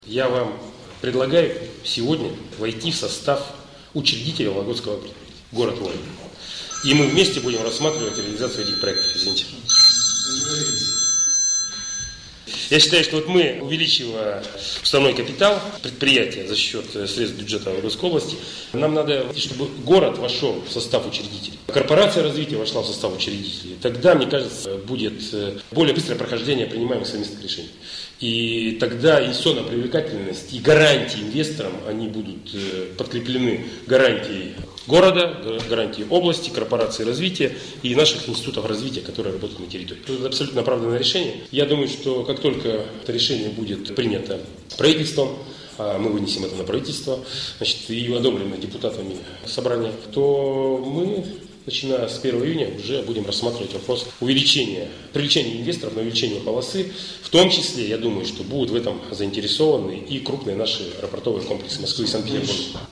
С таким предложением к Главе города Евгению Шулепову обратился губернатор Олег Кувшинников. Это произошло во вторник, 4 марта, в ходе подписания соглашения между Правительством региона и Администрацией Вологды.